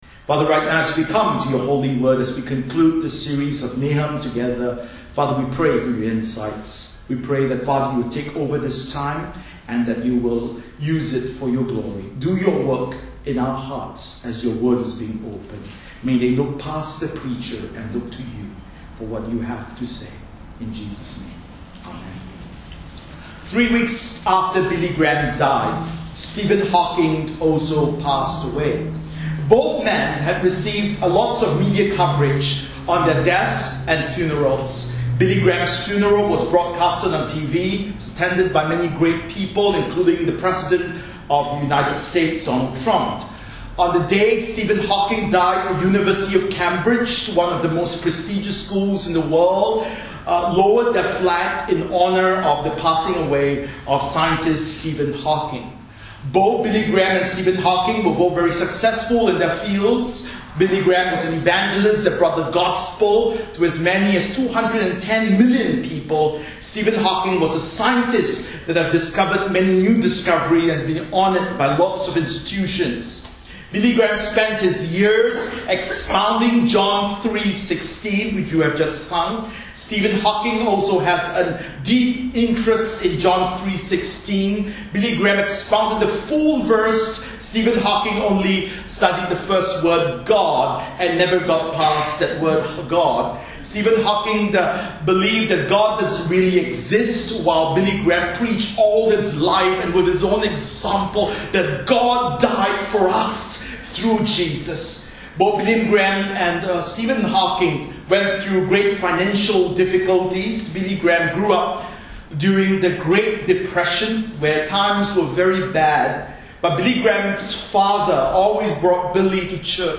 Bible Text: Nahum 3:12-19 | Preacher